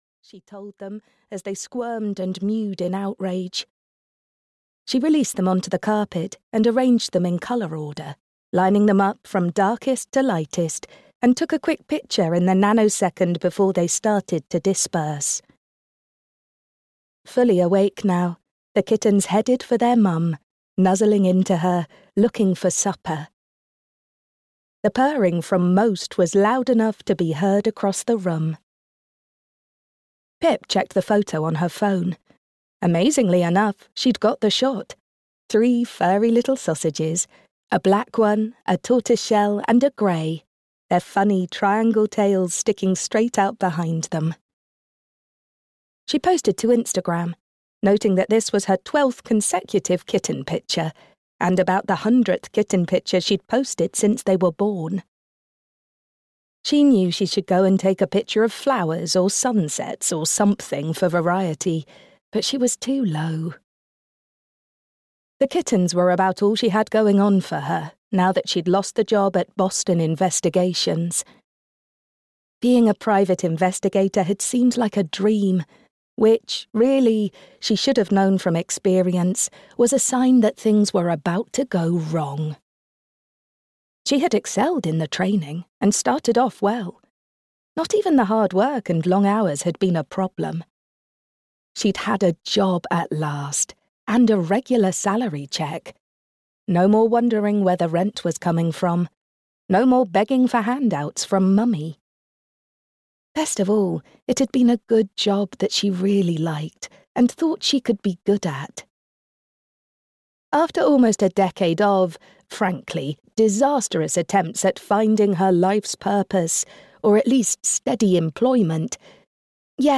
The Museum Murder (EN) audiokniha
Ukázka z knihy